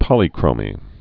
(pŏlē-krōmē)